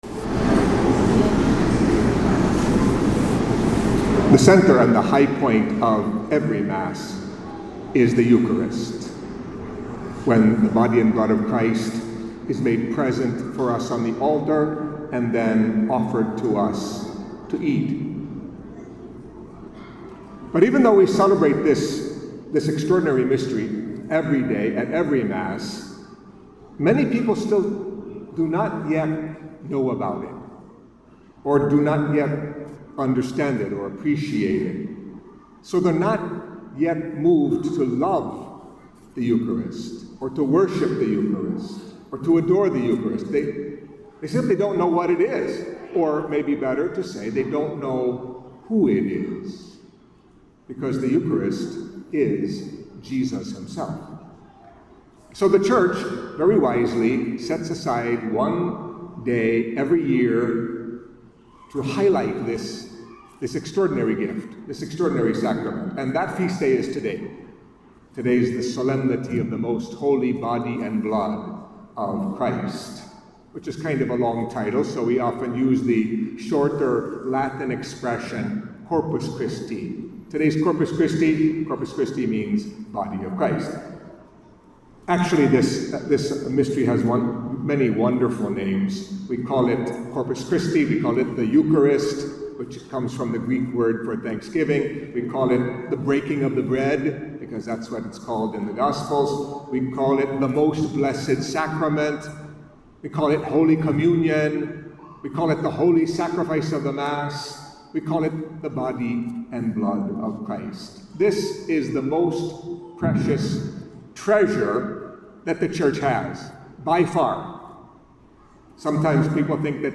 Catholic Mass homily for Solemnity of The Most Holy Body and Blood of Christ (Corpus Christi)